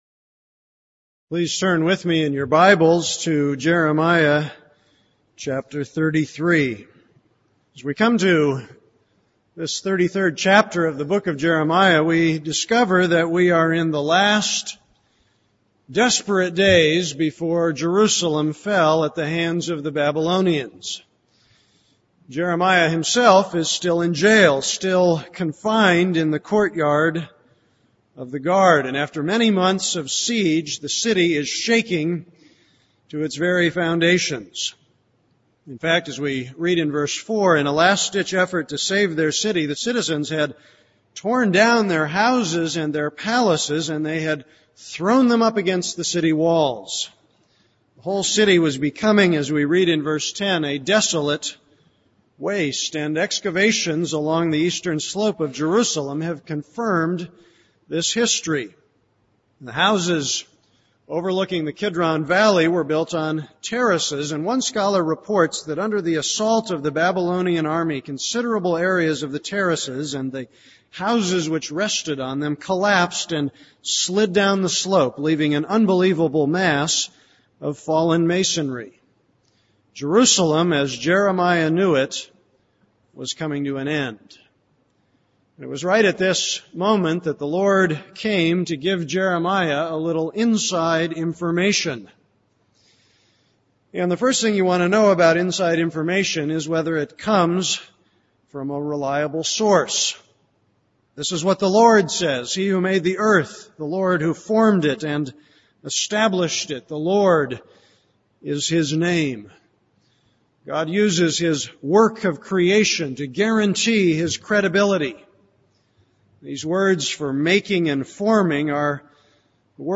This is a sermon on Jeremiah 33:1-11.